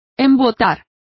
Complete with pronunciation of the translation of blunted.